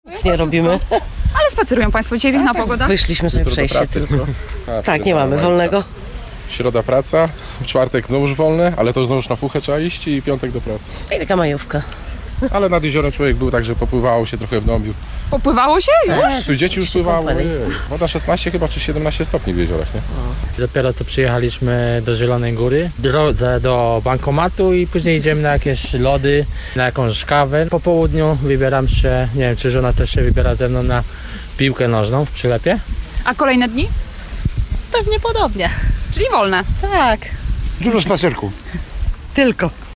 Zapytaliśmy ich, jakie mają plany na najbliższe dni: